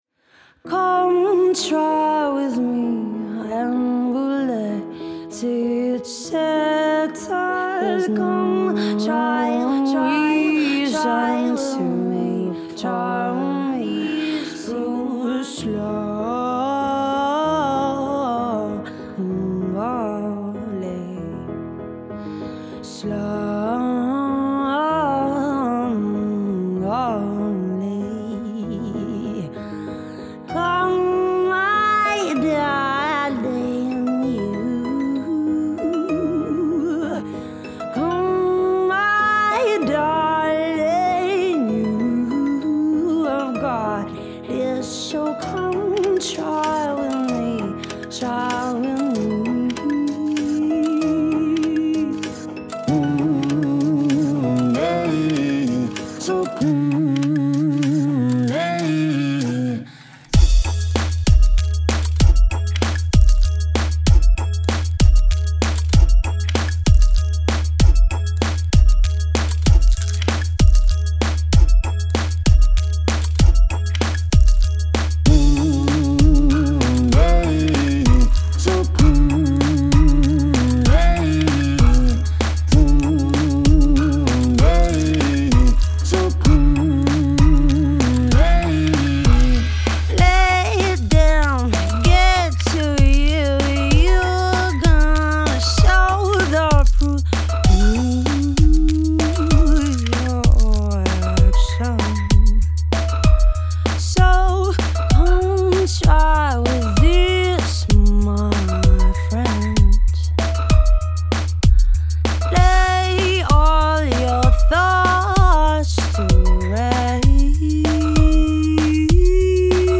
Genre: Multi-genre / EDM / Dubstep / D&B